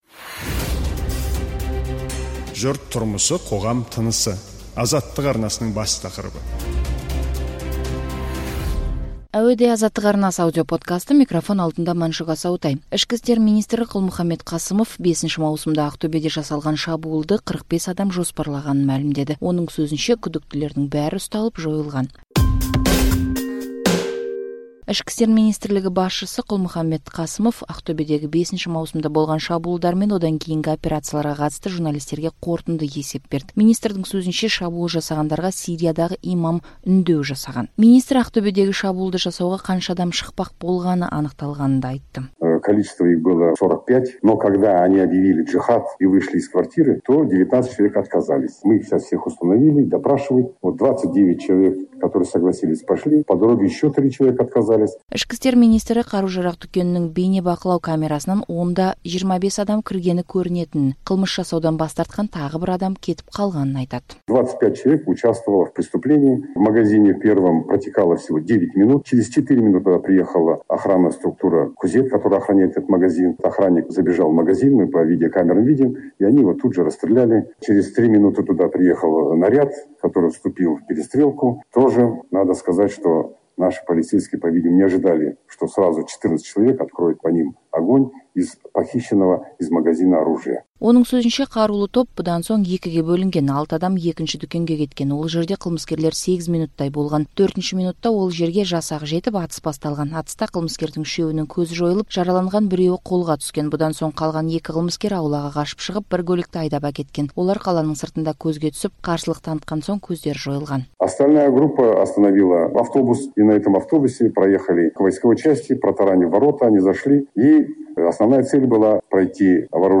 Ішкі істер министрі Қалмұханбет Қасымов Ақтөбедегі 5 маусымда болған шабуылдар мен одан кейінгі операцияларға қатысты журналистерге қорытынды есеп берді.